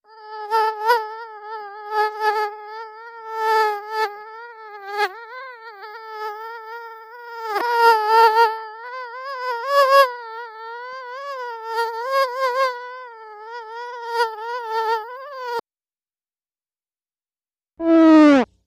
Animals-insects Single|Mosquito | Sneak On The Lot
Mosquito ( fake ) ends with bee fart ( real )